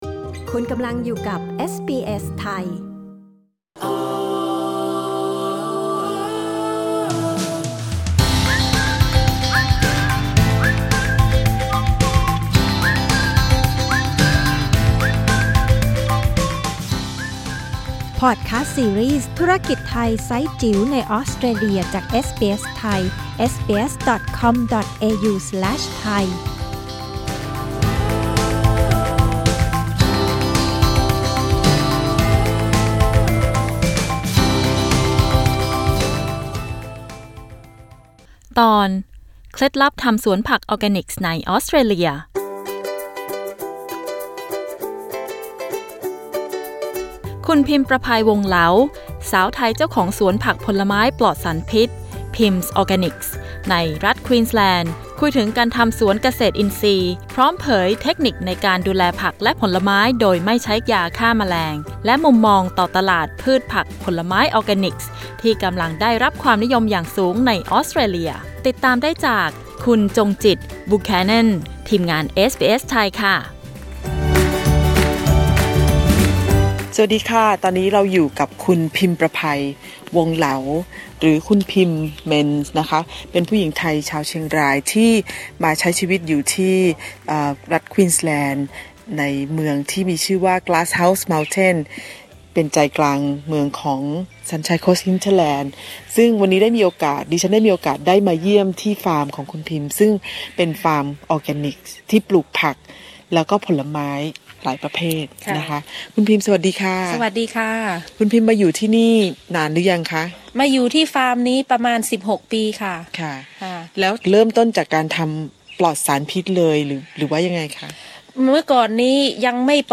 กดปุ่ม 🔊 ที่ภาพด้านบนเพื่อฟังสัมภาษณ์เรื่องนี้